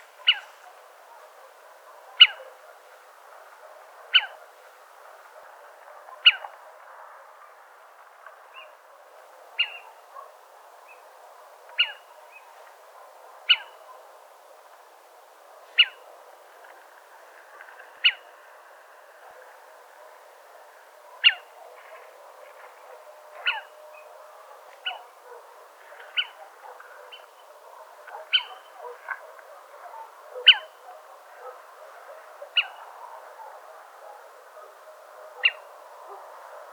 ATHENE NOCTUA - LITTLE OWL - CIVETTA